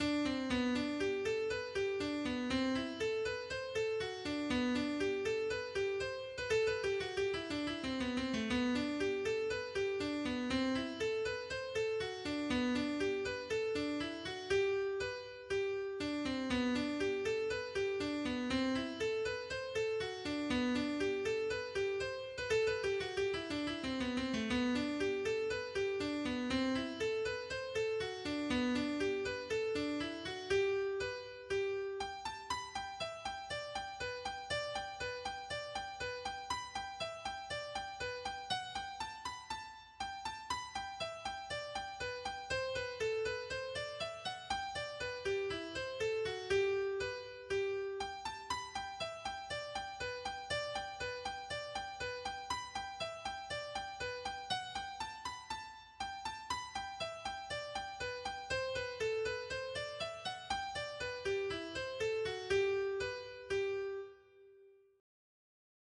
hornpipe